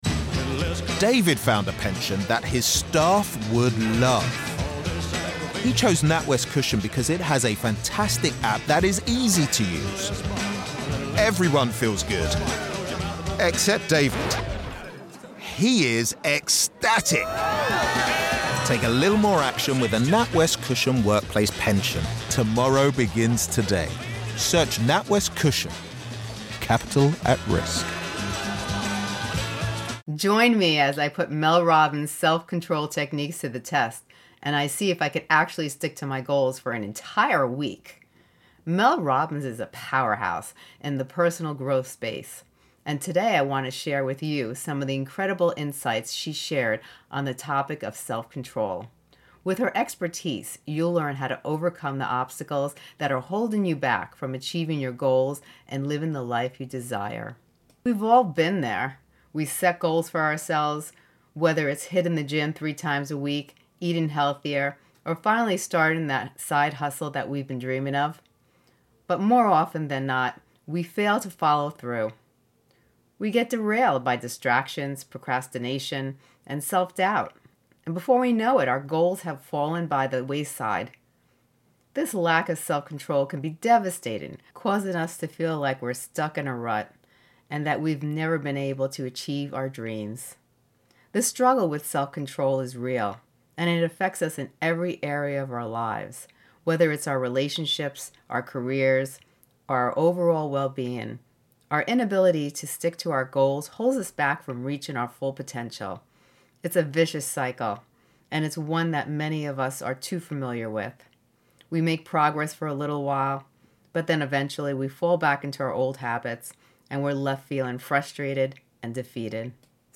Discover the power of self-control with Mel Robbins at the Personal Growth Conference! In this inspiring talk, Mel shares practical techniques to help you overcome procrastination, build confidence, and achieve your goals.